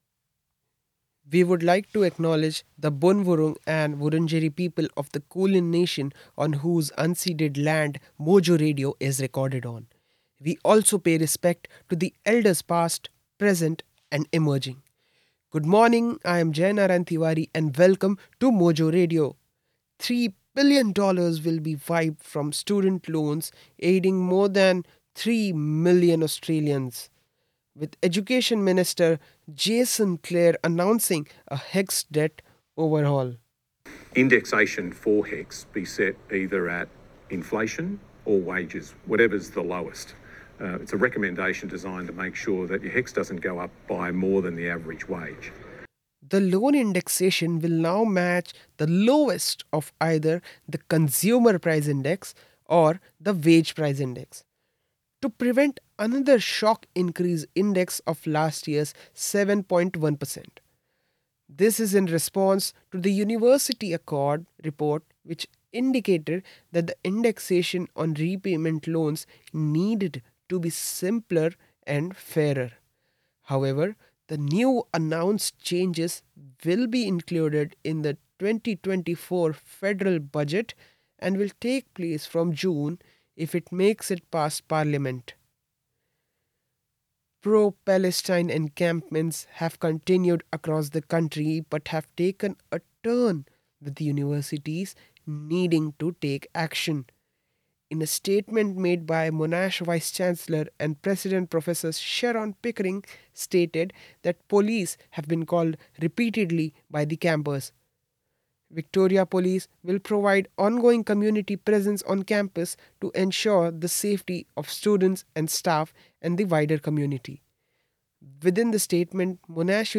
MOJO RADIO BULLETIN, MAY 10